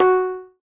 pling.ogg